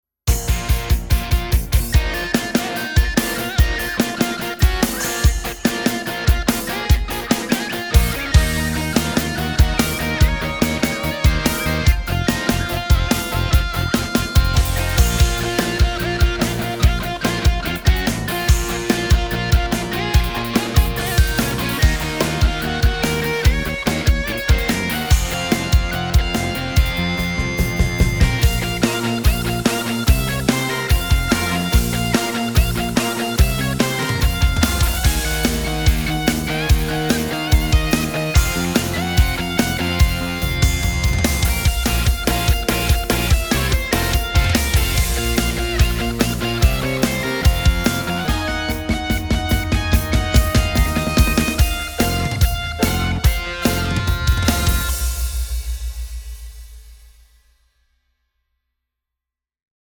מקצב - אשכול מקצבים
מצ''ב מקצב רוק שלי, נבנה על טיירוס 5 מהצלילים שבתוך הכלי בלבד, ללא דגימות כלל.